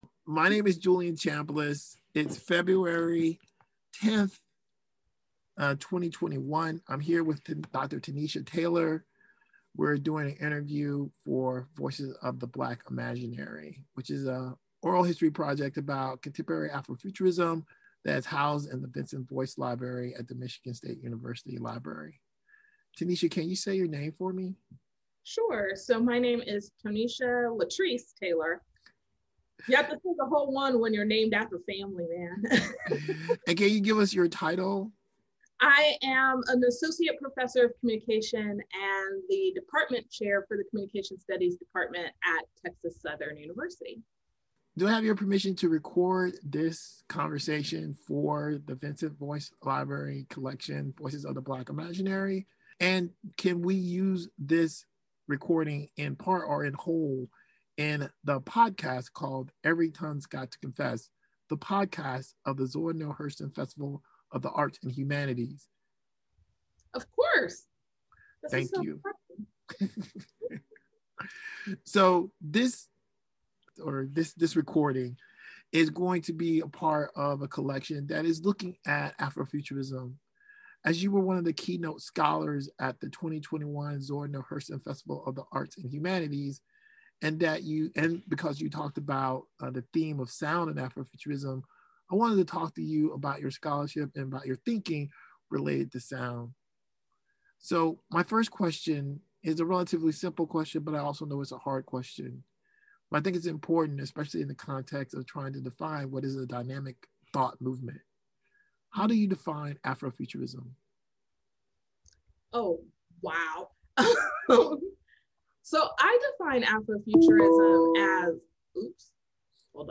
Interviewed on zoom
Interviews Sound recordings